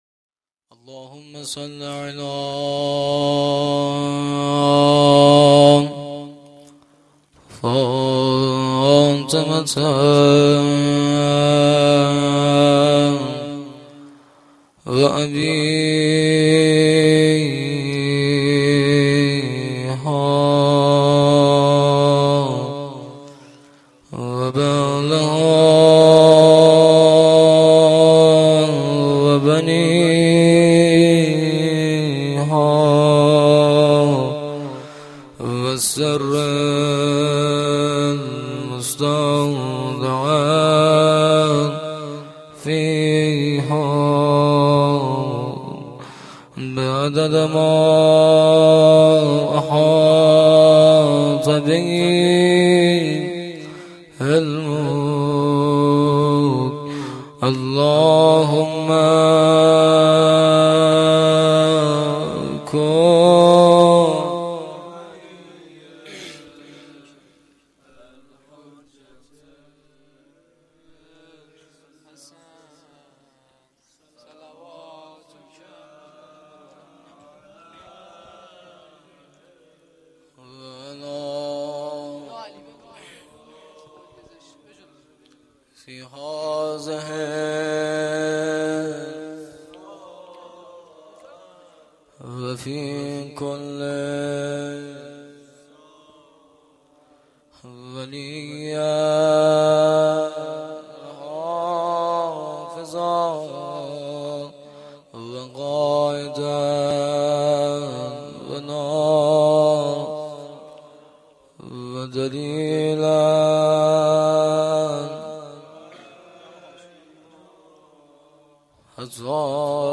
روضه العباس
roze.mp3